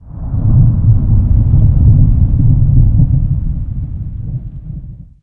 thunder20.ogg